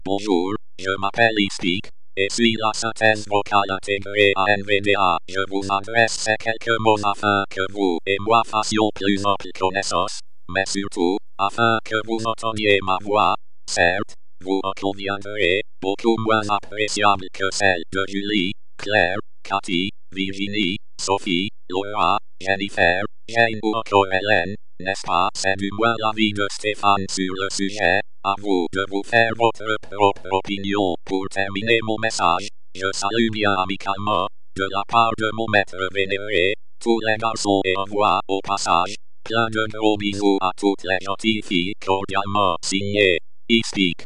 Texte de démonstration lu par Espeak, synthèse vocale intégrée à la revue d'écran gratuite NVDA
Écouter la démonstration d'Espeak, synthèse vocale intégrée à la revue d'écran gratuite NVDA